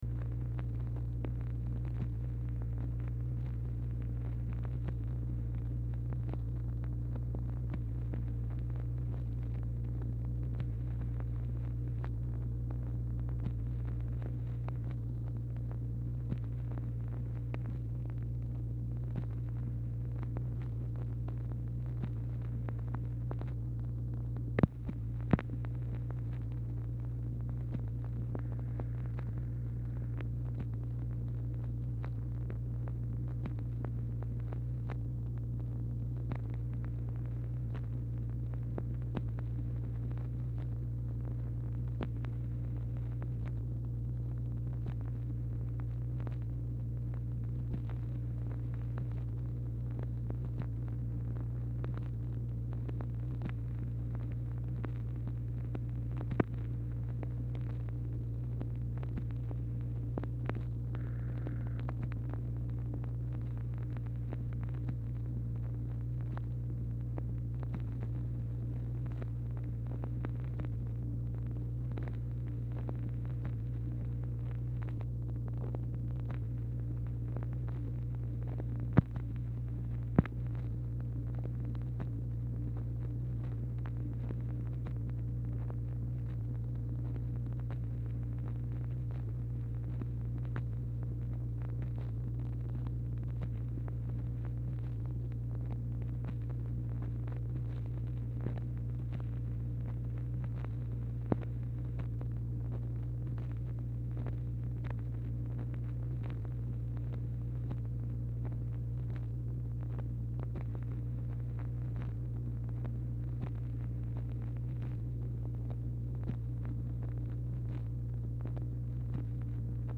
Telephone conversation # 1163, sound recording, MACHINE NOISE, 1/2/1964, time unknown | Discover LBJ
Format Dictation belt
Specific Item Type Telephone conversation